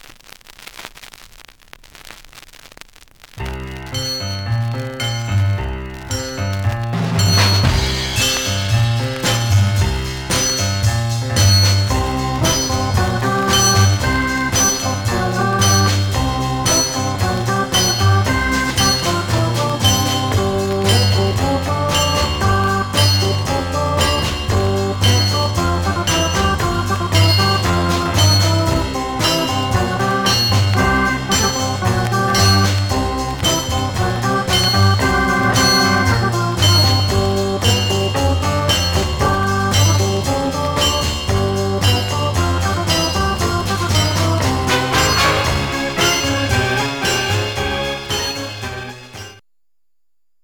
Some surface noise/wear Stereo/mono Mono
R & R Instrumental